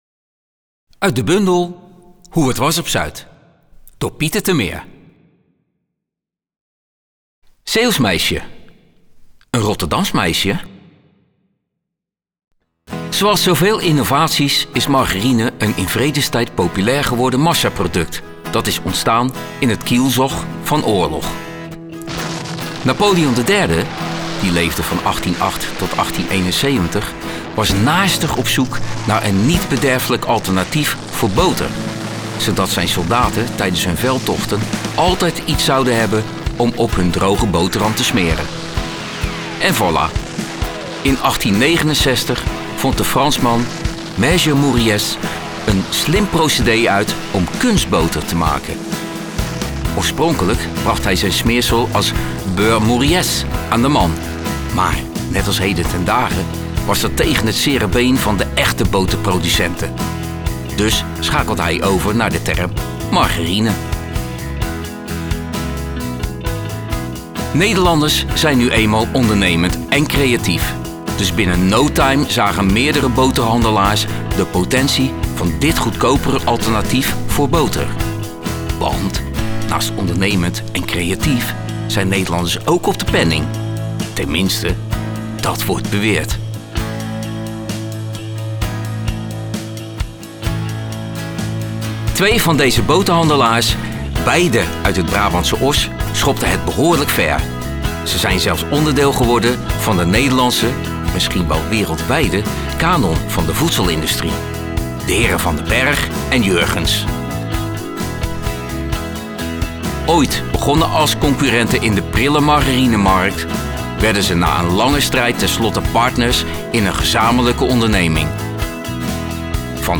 Voorbeelden complete verhalen: